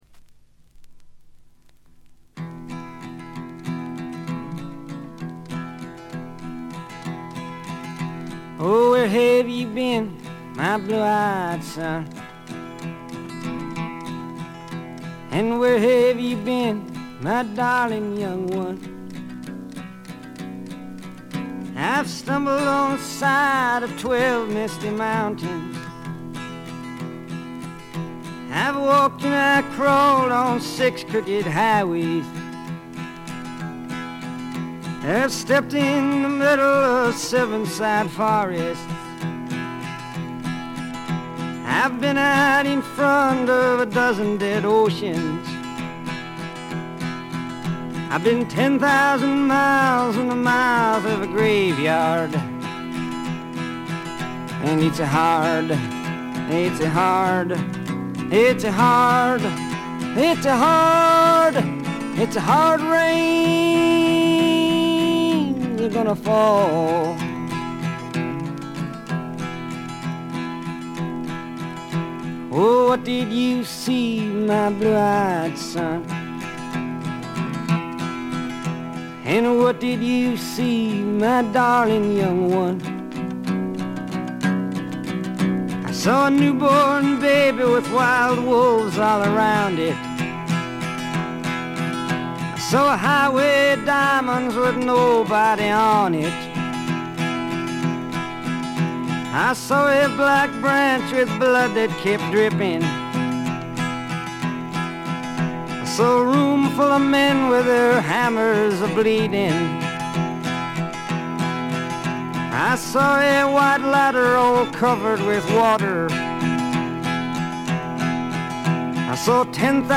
全体にバックグラウンドノイズ、チリプチ多め大きめですが音は見た目よりずっといい感じです。
試聴曲は現品からの取り込み音源です。